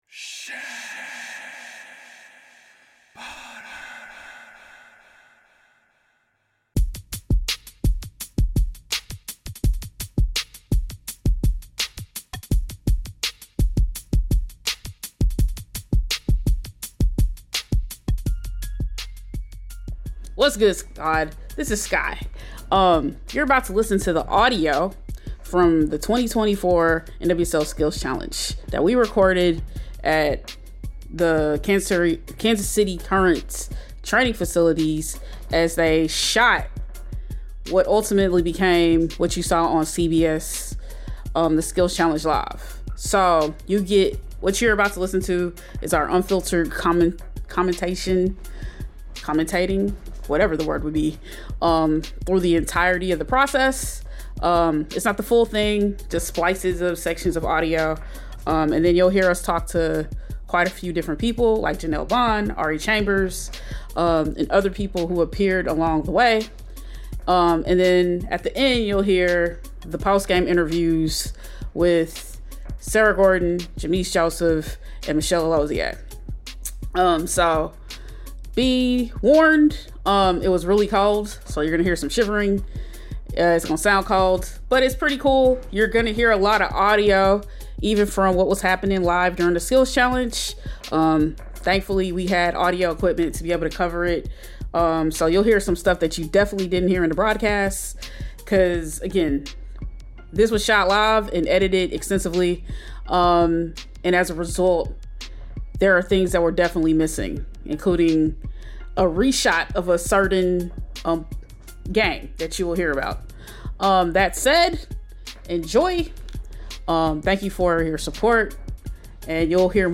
Episode 115 NWSL 2024 Skills Challenge Commentating and Post Event Interviews
Additionally, this episode includes post-event interviews with Sarah Gorden, Jameese Joseph and Michele Alozie.